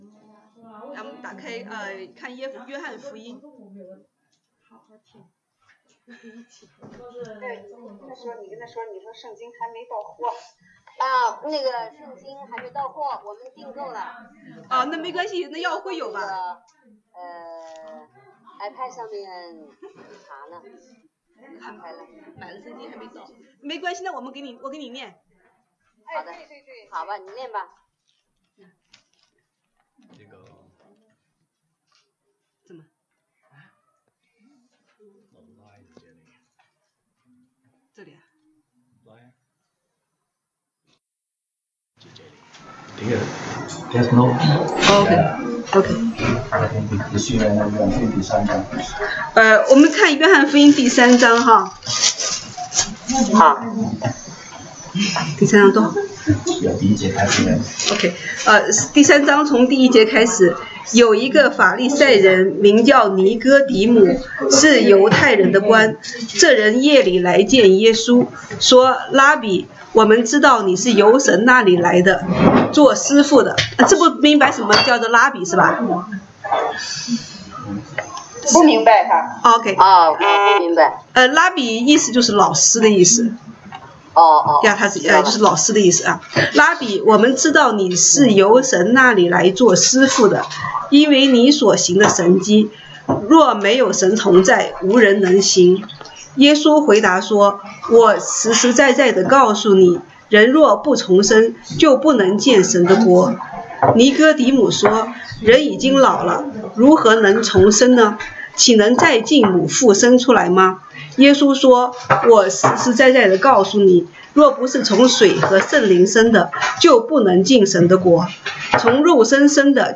週一國語研經 Monday Bible Study « 西堂證道